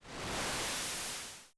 Wave_Crash.wav